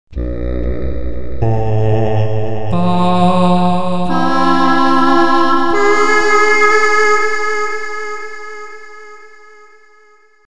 Enregistrer une voix  produisant quelques notes
Sélectionner une note,  en modifier sa hauteur